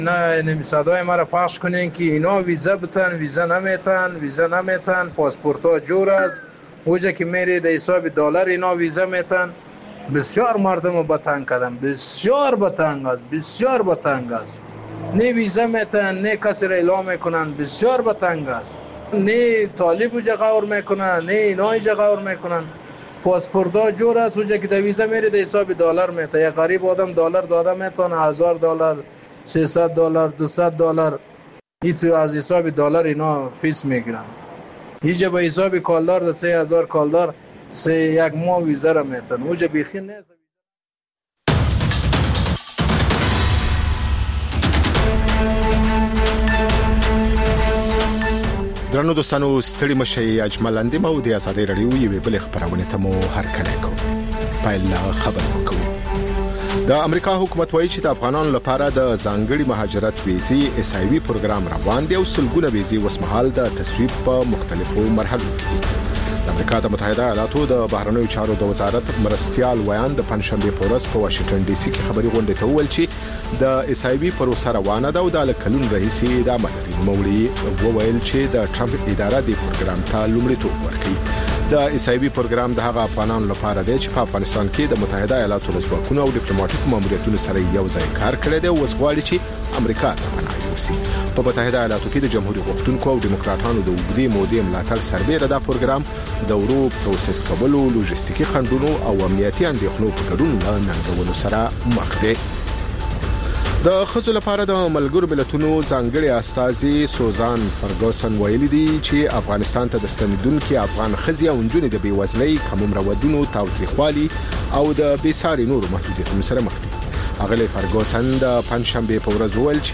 ژوندي نشرات - ازادي راډیو